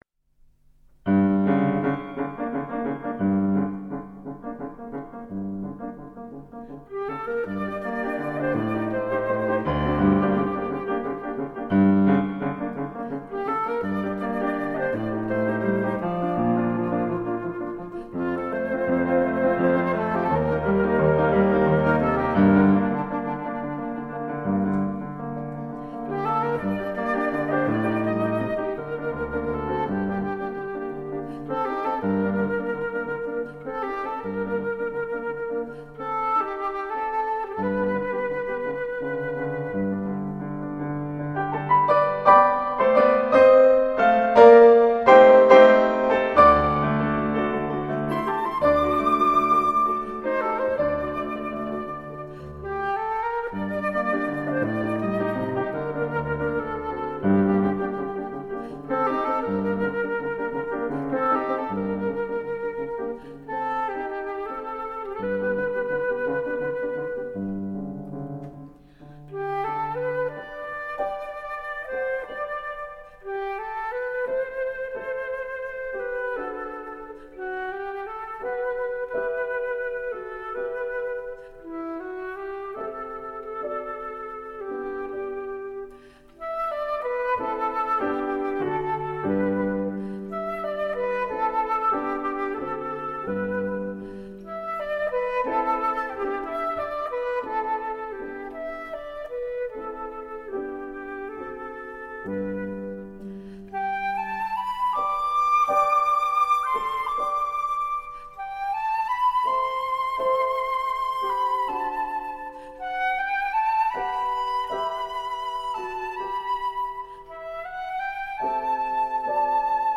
西洋古典音乐及小品
小品式的音乐，长笛、钢琴和竖琴的组合
录音的音色也是赏心悦耳
这三件乐器的录音效果则非比寻常，声部的平衡感和音质的透彻感绝可媲美世界同类制作。